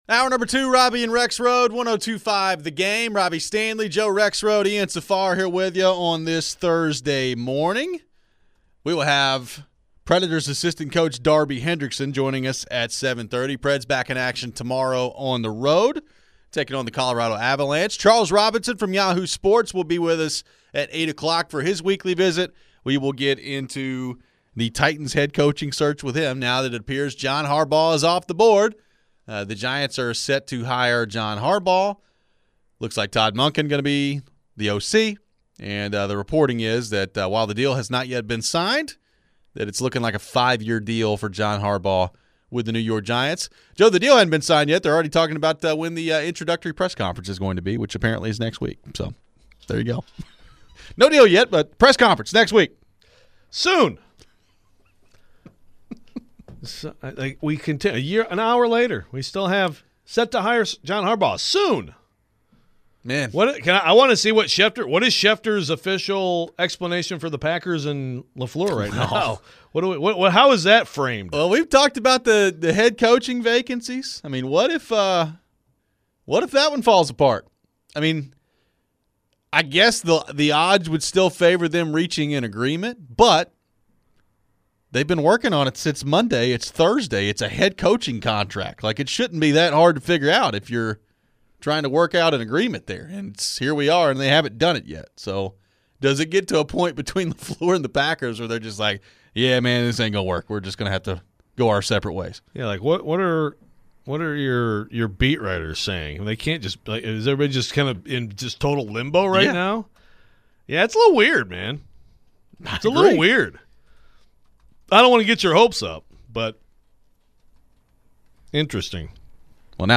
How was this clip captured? We head back to the phones on the coaching search for the Titans after John Harbaugh agrees to a deal with the Giants. There was some news on the NFL QB draft class after Oregon's Dante Moore announced he is going to return to school.